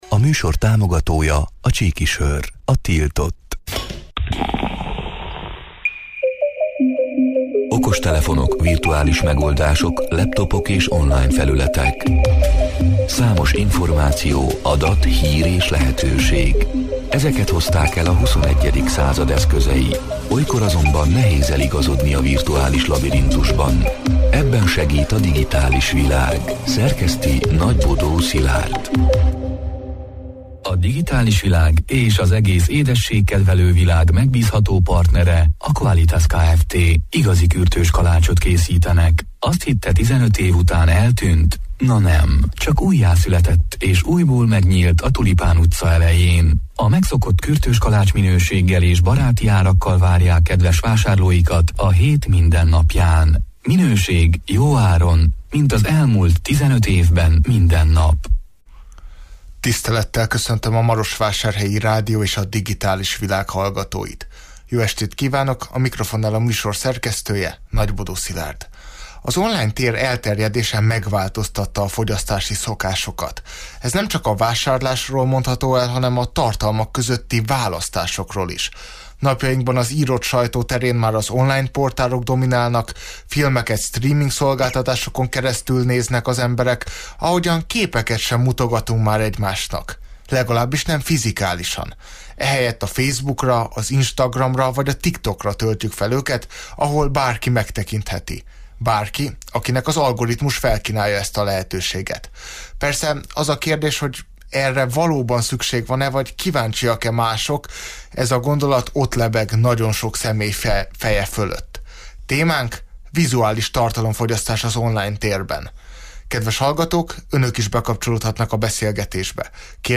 A Marosvásárhelyi Rádió Digitális Világ (elhangzott: 2023. július 11-én, kedden este nyolc órától élőben) c. műsorának hanganyaga: Az online tér elterjedése megváltoztatta a fogyasztási szokásokat.